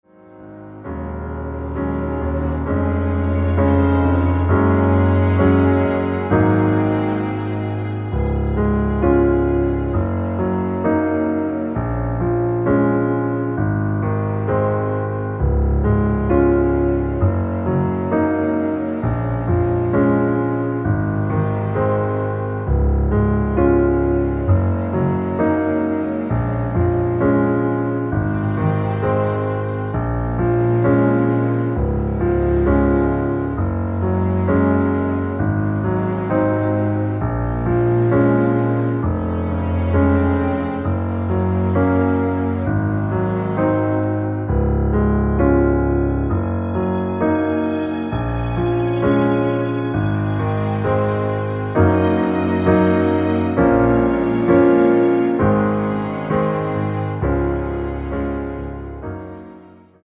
내린 MR 입니다.
Db
◈ 곡명 옆 (-1)은 반음 내림, (+1)은 반음 올림 입니다.
앞부분30초, 뒷부분30초씩 편집해서 올려 드리고 있습니다.
중간에 음이 끈어지고 다시 나오는 이유는